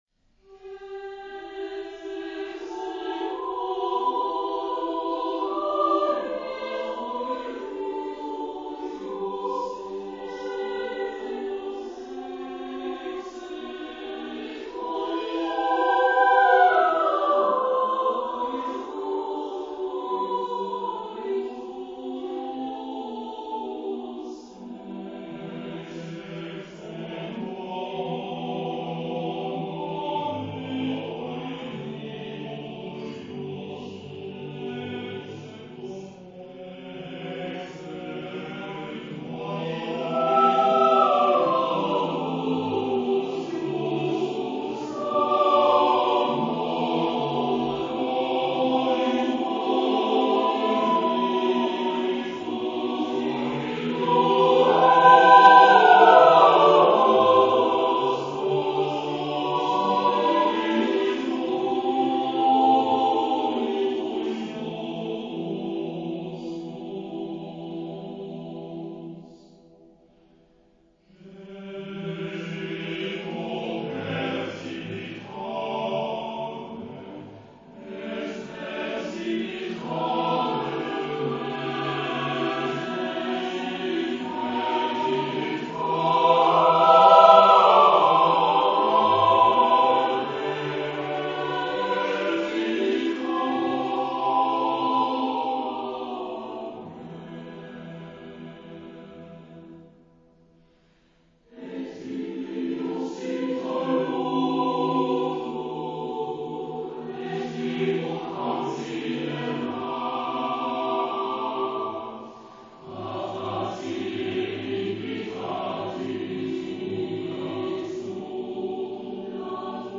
Genre-Stil-Form : geistlich ; Motette ; Zeitgenössisch
Charakter des Stückes : melancholisch ; schmerzlich
Gattung : SSAATTBB (8 gemischter Chor Stimmen )
Tonart : e-moll